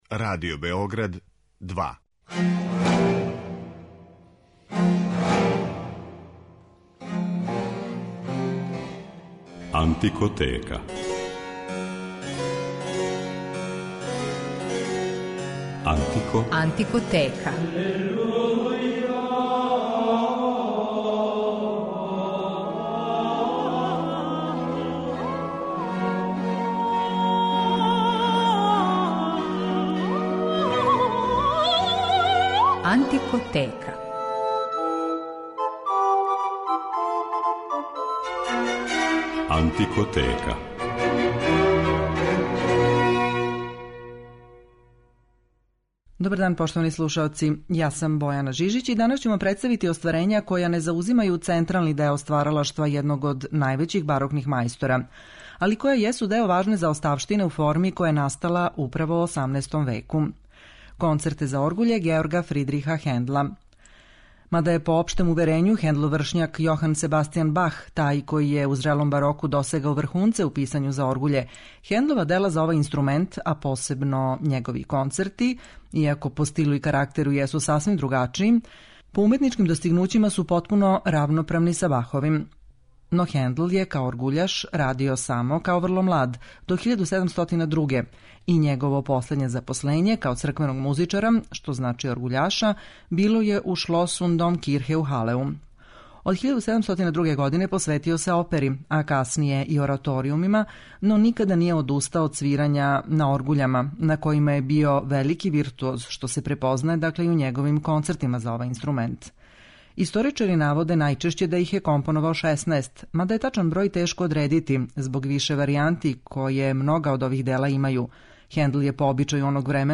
Хендлови концерти за оргуље